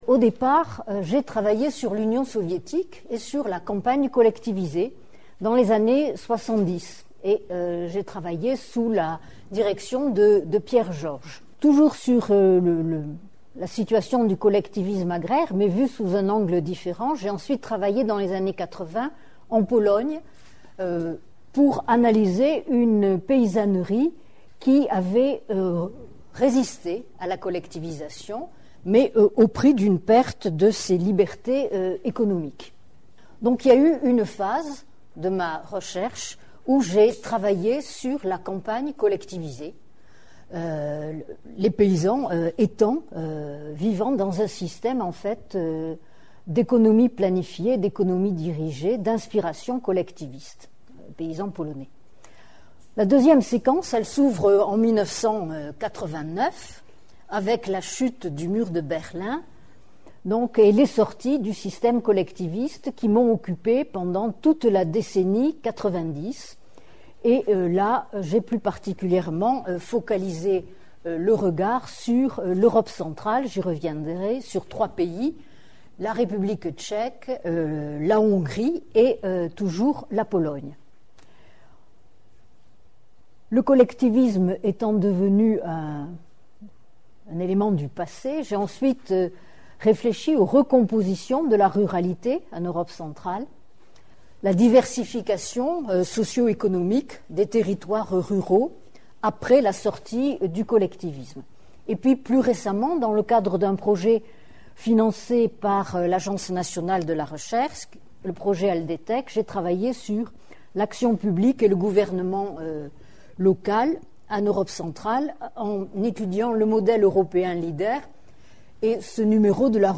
Le séminaire se propose d'analyser les rapports entre le temps économique et social de la transformation postsocialiste et les temps de genèse et de recomposition des formes spatiales qui structurent les territoires ruraux.